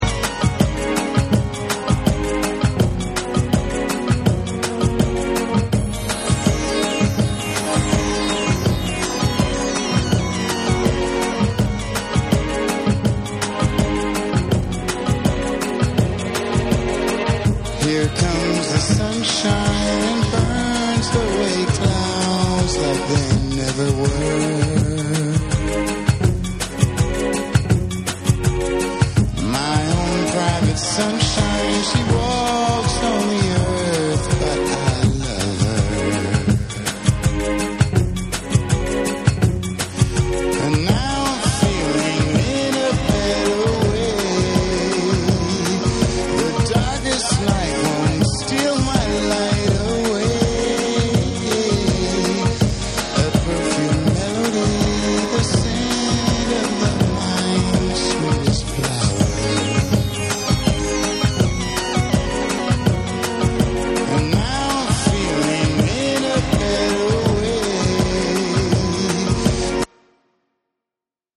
ボッサ調のリズムに美しいストリングスワーク、その間を縫う様に入るヴォーカルやシンセリフも素晴らしいSAMPLE1
TECHNO & HOUSE / ORGANIC GROOVE